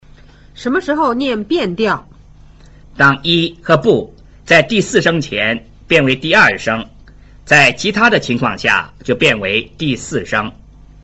當 ““在第四聲前變為第二聲, 在其它的情況下”一” 和”不”就變成第四聲。